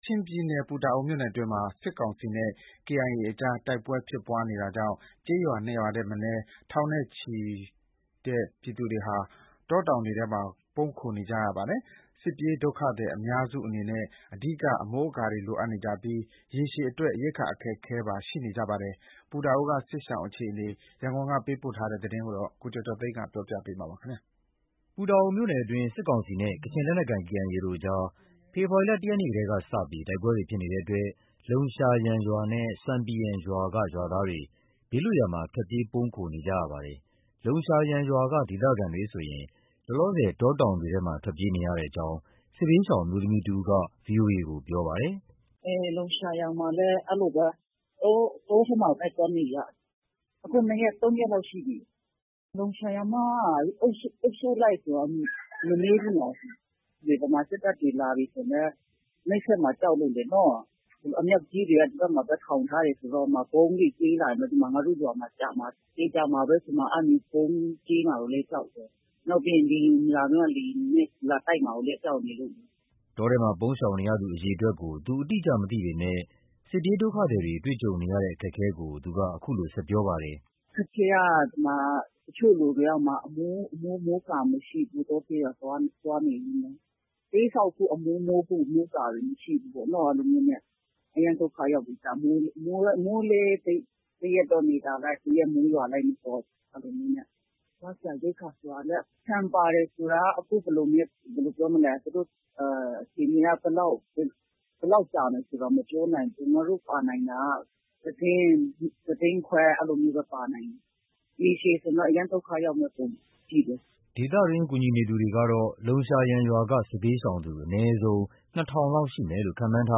ပူတာအိုမြို့နယ်အတွင်း စစ်ကောင်စီနဲ့ကချင်လက်နက်ကိုင် KIA တို့ကြား ဖေဖော်ဝါရီလ ၁ ရက်နေ့ကစပြီး တိုက်ပွဲတွေဖြစ်နေတဲ့အတွက် လုံရှာယန်ရွာနဲ့ ဆွမ်ပီယန်ရွာက ရွာသားတွေ ဘေးလွတ်ရာမှာ ထွက်ပြေးပုန်းခိုနေကြရပါတယ်။ လုံရှာယန်ရွာက ဒေသခံတွေဆိုရင် လောလောဆယ် တောတောင်တွေထဲမှာ ထွက်ပြေးနေရကြောင်း စစ်ဘေးရှောင် အမျိုးသမီးတဦးက VOA ကို ပြောပါတယ်။
ဒေသတွင်း ကူညီသူတွေကတော့ လုံရှာယန်ရွာက စစ်ဘေးရှောင်သူ အနည်းဆုံး ၂,၀၀၀ လောက်ရှိမယ်လို့ ခန့်မှန်းထားပါတယ်။ လုံရှာယန်ရွာသားတွေလိုပဲ တောတောင်ထဲမှာ ပုန်းရှောင်နေရတဲ့ ဆွမ်ပီယန်ရွာသား ၁,၀၀၀ ကျော်ရှိတယ်လို့ ဆွမ်ပီယန်ရွာသားတယောက်က ပြောပြပါတယ်။ ခုလို ပူတာအိုမြို့နယ်က တောတောင်တွေထဲမှာ ပုန်းရှောင်နေရတဲ့ စစ်ပြေးဒုက္ခသည်တွေအတွက် ရိက္ခာပို့ပေးနိုင်ဖို့လည်း အခက်အခဲတွေ ရှိနေပါတယ်။ စစ်တပ်ဘက်က ကုန်ပစ္စည်းတွေကို ကန့်သတ်နေတယ်လို့ စစ်ဘေးရှောင်တွေကို ကူညီနေသူတဦးက အခုလို ပြောပါတယ်။